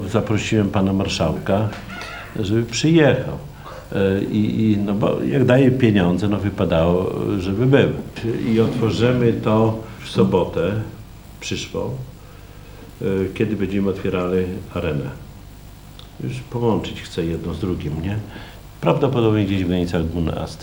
O szczegółach Czesław Renkiewicz, prezydent miasta.